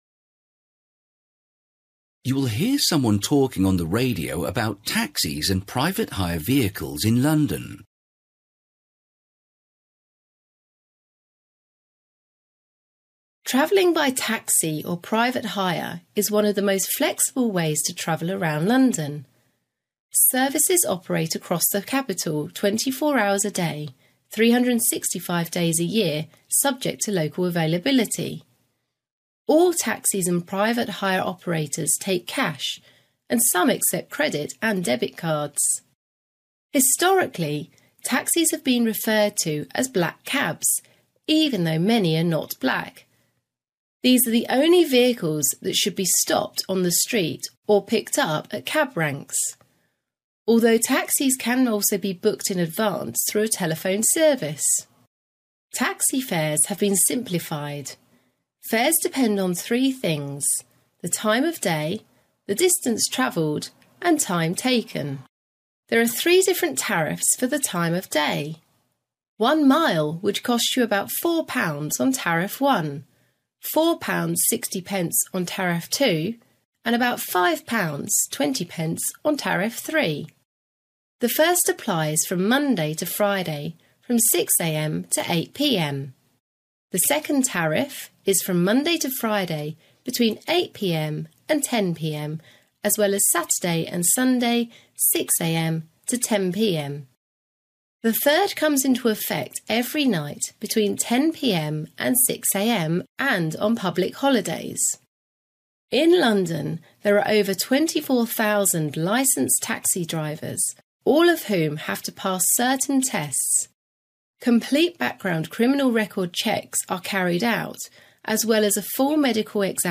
You will hear someone talking on the radio about taxis and private hire vehicles in London.